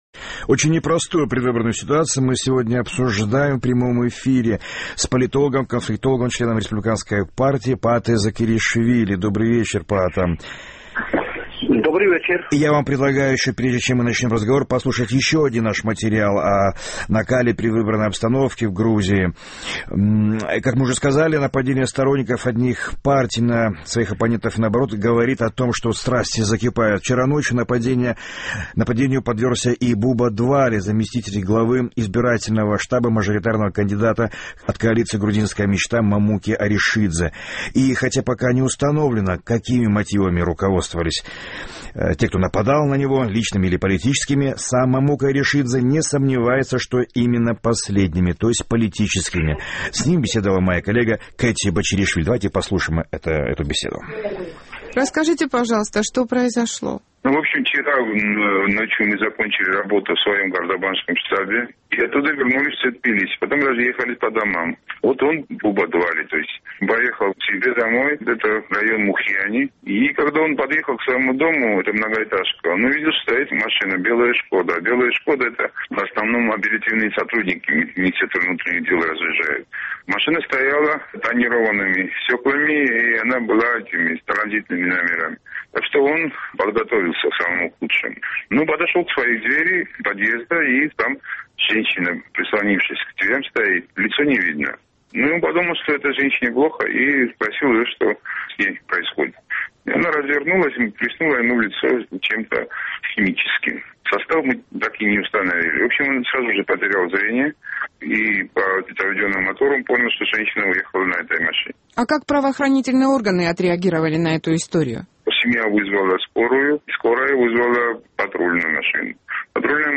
Очень непростую предвыборную ситуацию в Грузии мы обсуждаем в прямом эфире с конфликтологом Паатой Закареишвили.